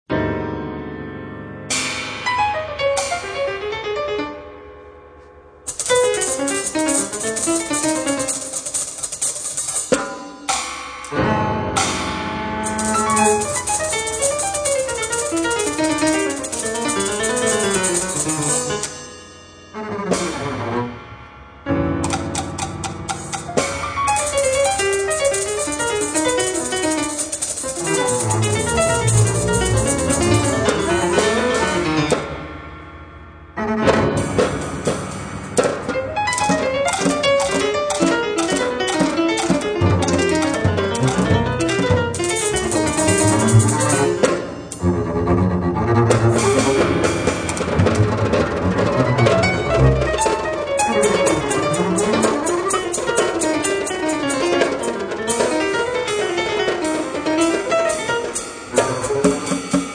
pianoforte
contrabbasso
batteria